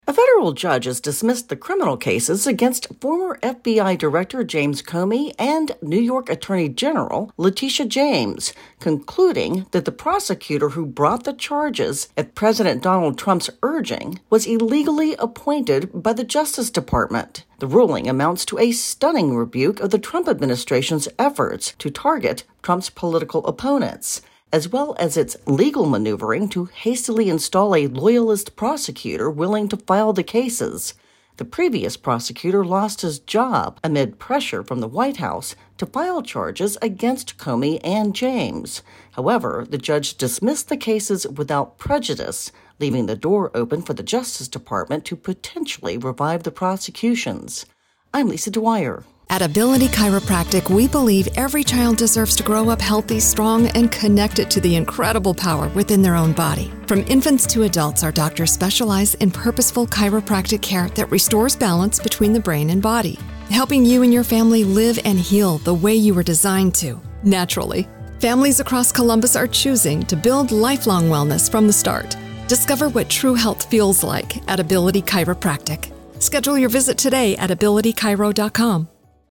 reports on a major ruling in two high profile political cases.